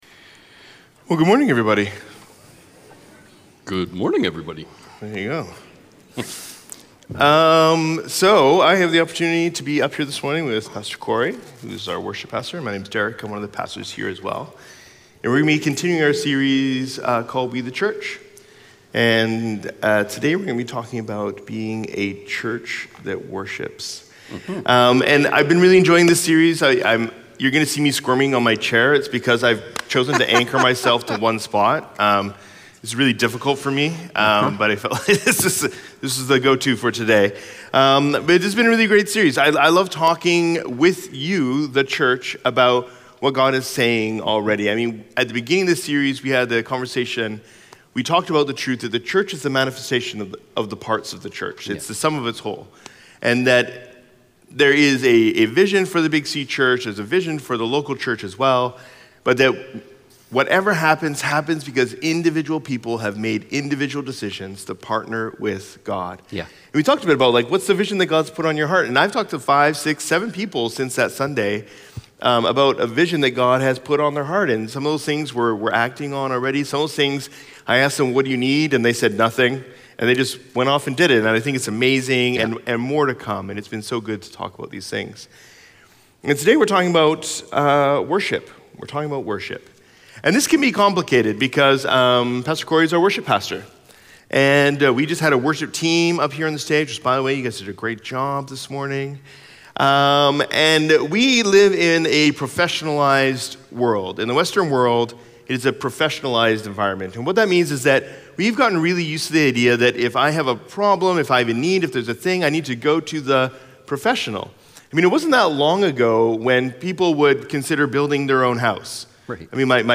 Sermons | Riverside Community Church